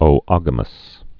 (ō-ŏgə-məs)